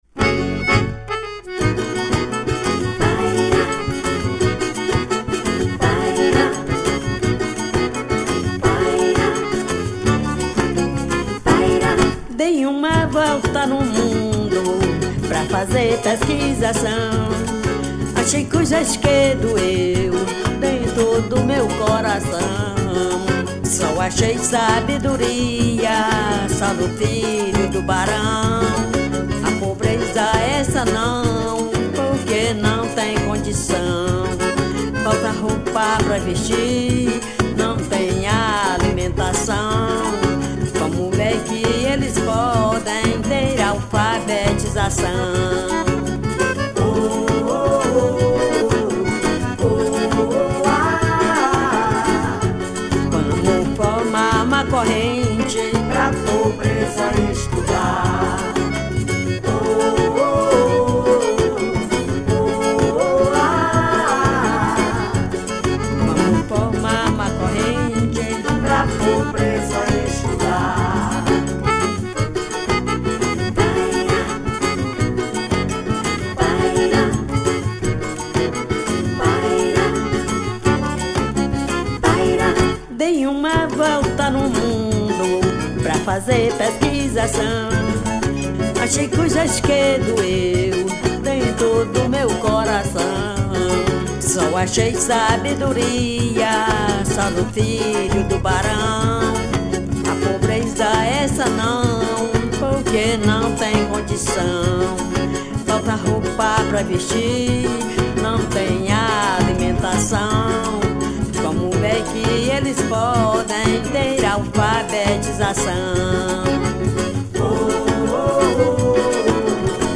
126   03:14:00   Faixa:     Forró